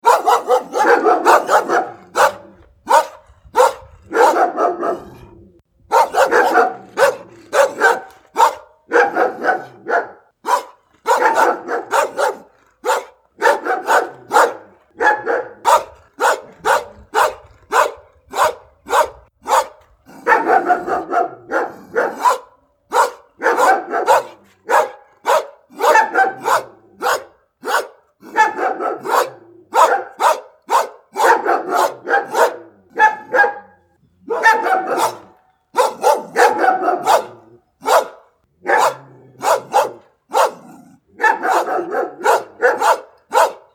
Barking Two Large Dogs Sound Button - Free Download & Play